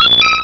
Cri d'Hypotrempe dans Pokémon Rubis et Saphir.